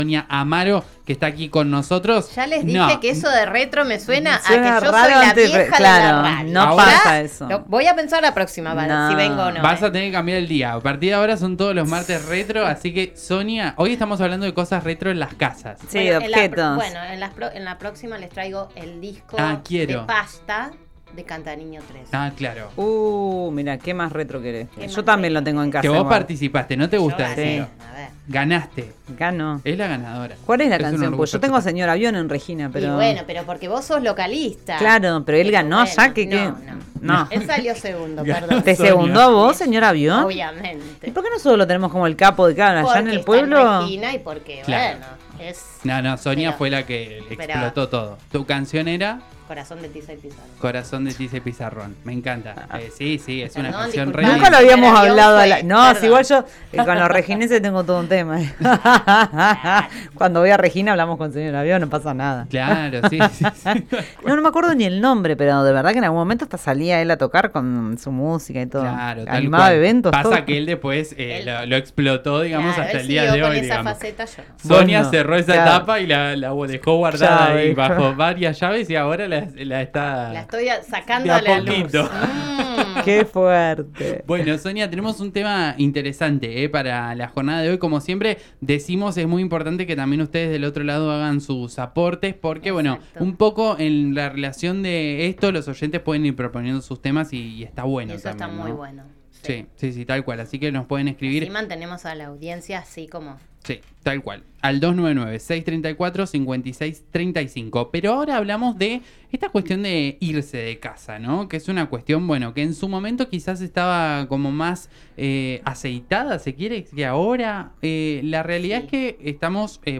La profesora de psicología explica que la adultescencia no es nueva, aunque hoy se nombre más.